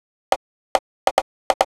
Techno / Drum / PERCUSSN035_TEKNO_140_X_SC2.wav